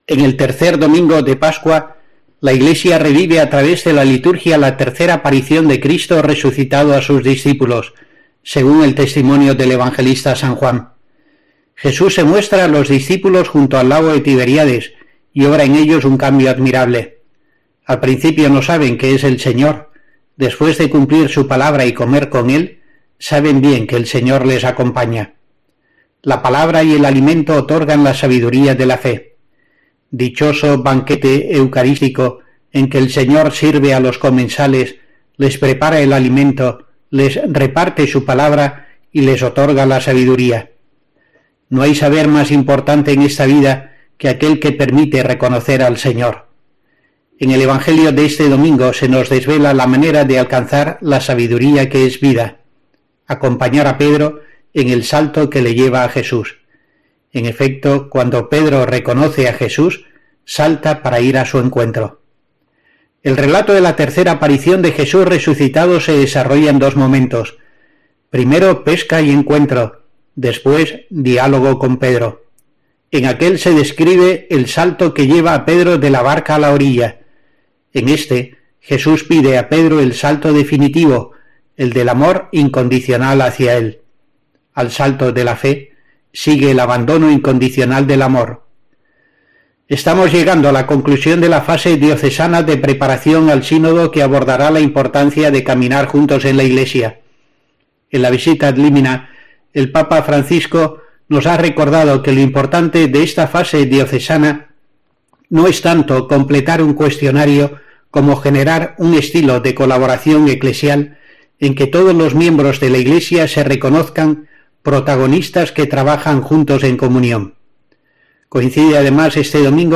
El obispo de Asidonia-Jerez aprovecha su comentario semanal para COPE del Tercer Domingo de Pascua para analizar las apariciones de Jesús, la sinodalidad y el Primero de Mayo
Escucha aquí a monseñor José Rico Pavés con su reflexión semanal 29-04-22